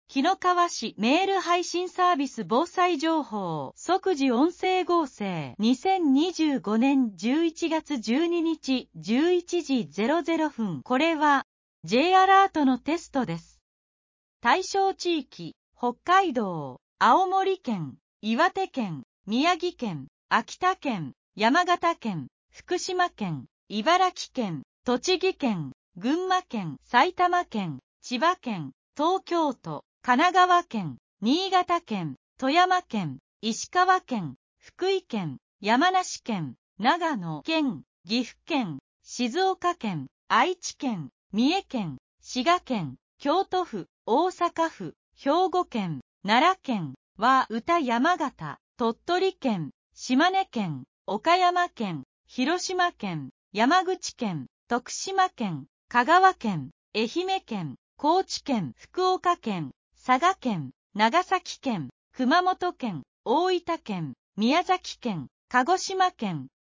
即時音声書換情報
紀の川市メール配信サービス【防災情報】 「即時音声合成」 2025年11月12日11時00分 これは、Jアラートのテストです。